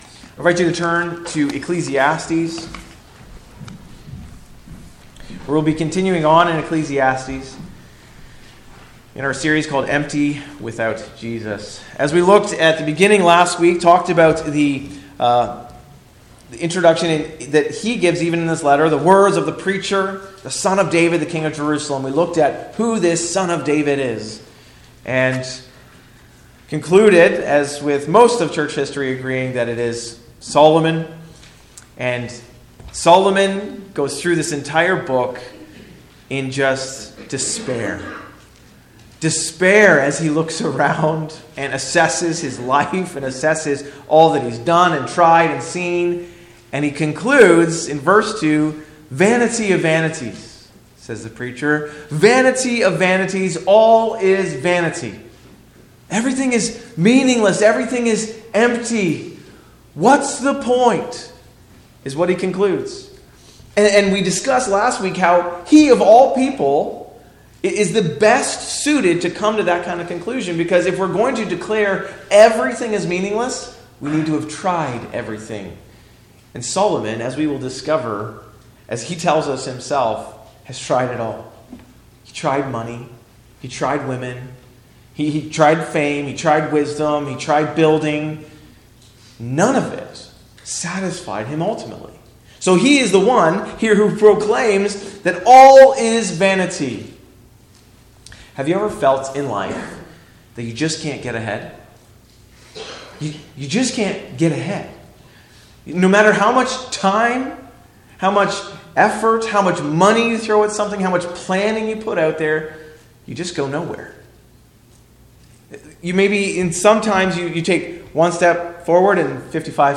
Sermons | West Lorne Baptist Church